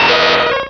Cri de Grotadmorv dans Pokémon Rubis et Saphir.